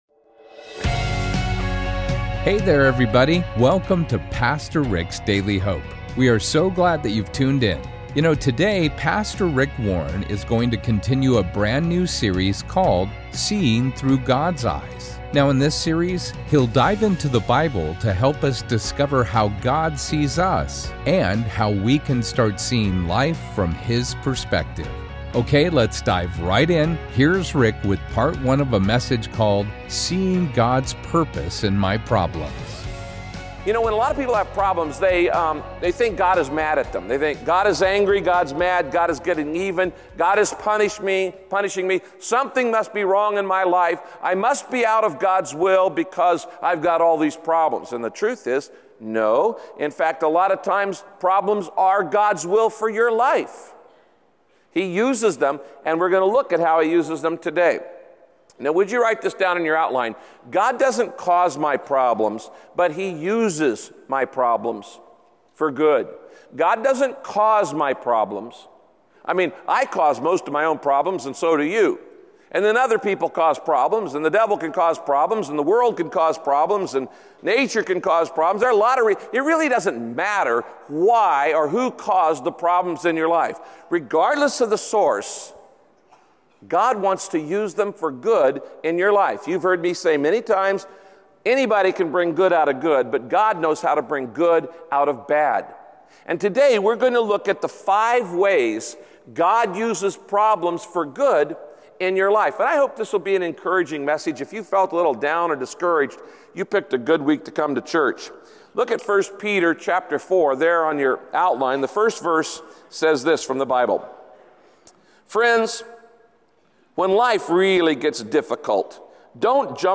In this message, Pastor Rick teaches how God uses your problems to direct you and correct you as he transforms you into the person he created you to be.